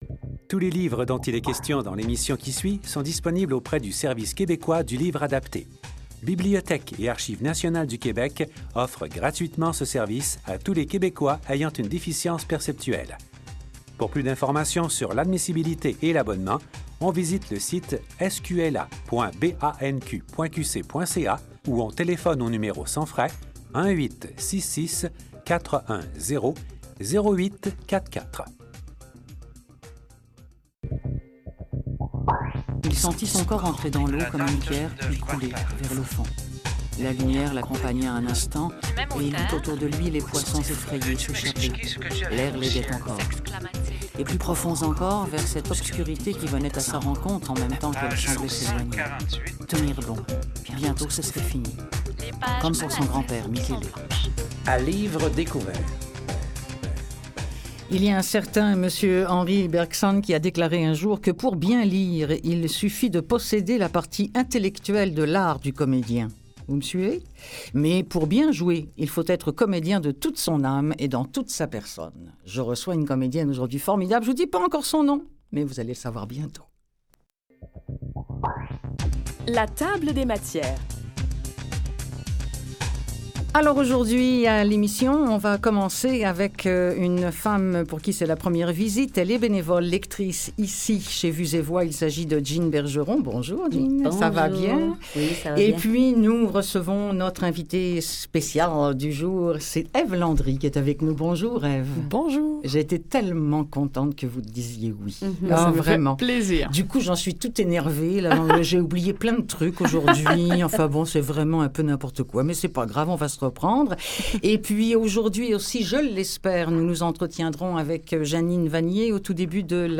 La comédienne Ève Landry est en studio